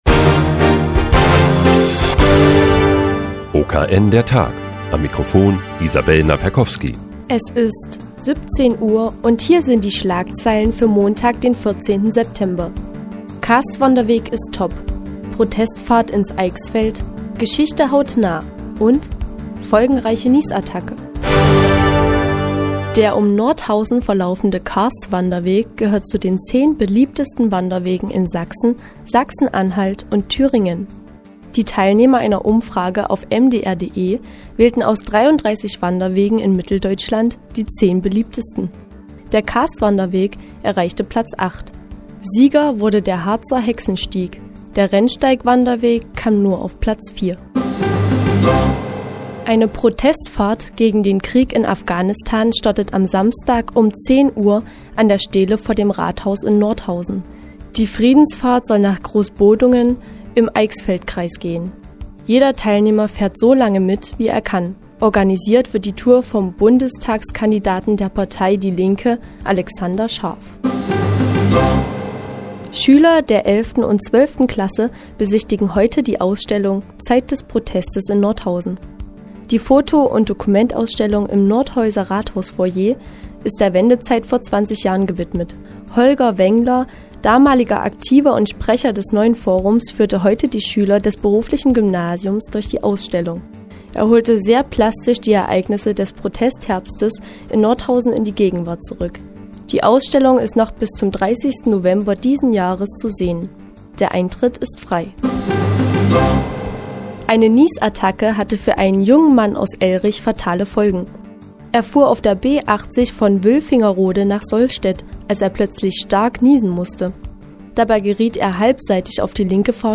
Die tägliche Nachrichtensendung des OKN ist nun auch in der nnz zu hören. Heute wird über den Karstweg, eine Protestfahrt, einen Ausstellungsbesuch und eine folgenreiche Niesattacke berichtet.